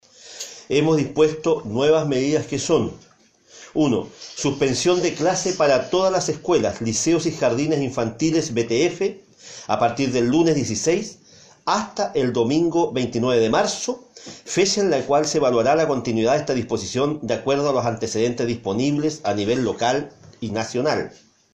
En dependencias del Arena Puerto Montt, el alcalde Gervoy Paredes informó de las medidas a aplicar en la capital regional para evitar contagios en consideración a la fase 3 en la que ha ingresado el país por este virus.